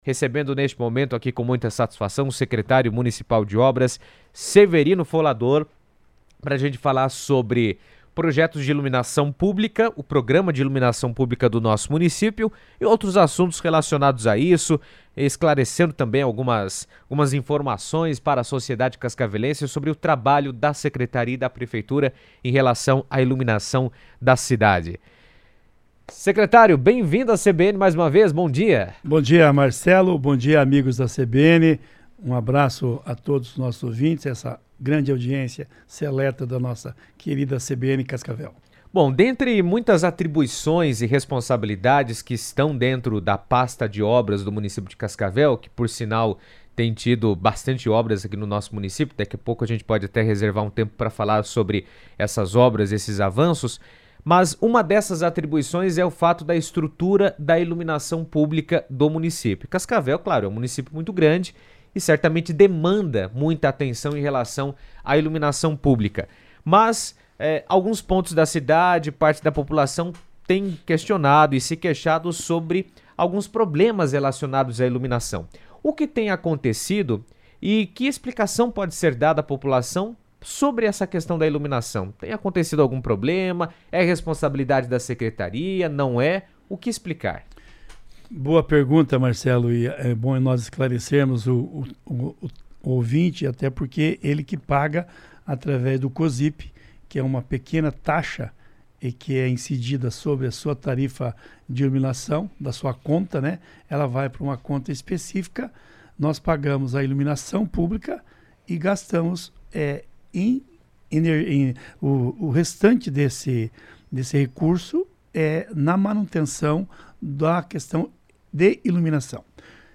O secretário de Obras de Cascavel, Severino Folador, esteve na CBN para falar sobre os problemas enfrentados na iluminação pública do município. Durante a entrevista, destacou os principais desafios e as medidas que estão sendo avaliadas para melhorar a qualidade do serviço oferecido à população.